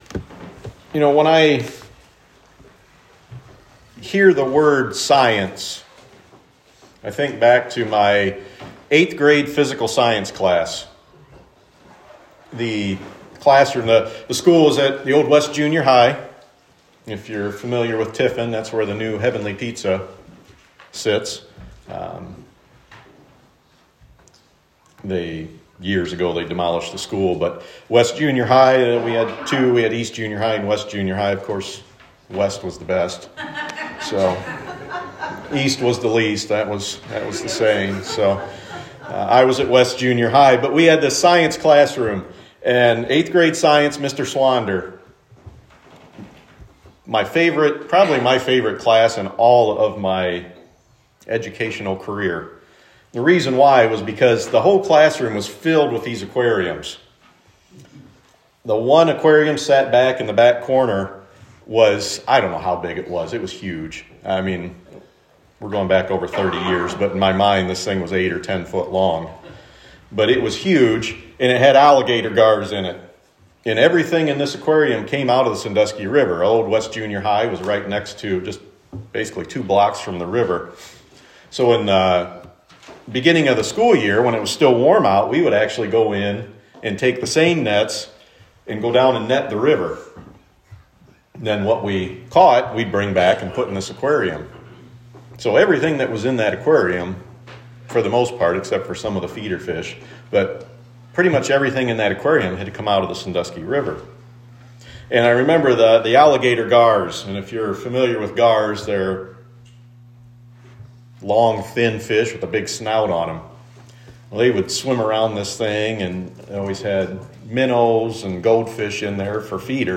FORT SENECA COMMUNITY CHURCH Home What We Believe Staff Our History Sermons The Creative Word